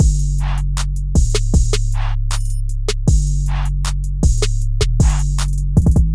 描述：ld drums from throwaway beat hmu for personal loops collabs allat
Tag: 156 bpm Hip Hop Loops Drum Loops 1.04 MB wav Key : F